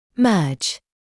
[mɜːʤ][мёːдж]сливаться, соединяться; слияние, объединение